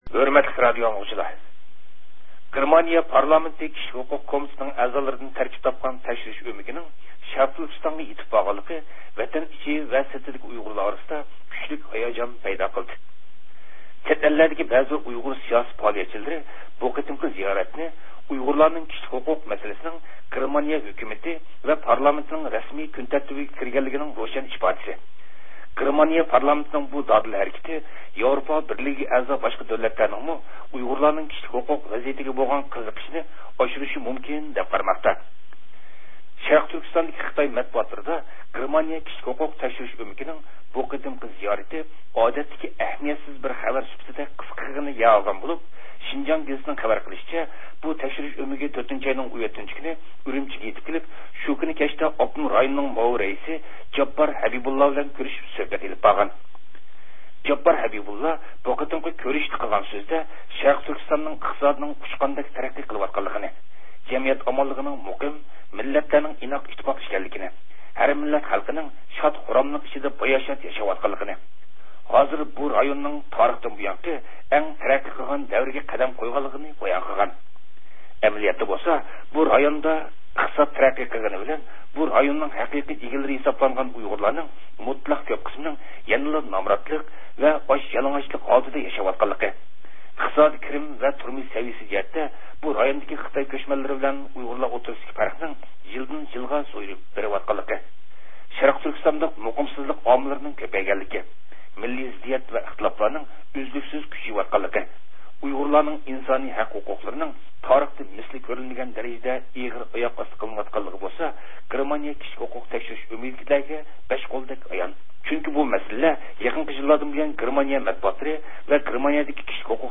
ﺋﻮﺑﺰﻭﺭﭼﯩﻤﯩﺰ